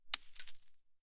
Bullet Shell Sounds
generic_leaves_3.ogg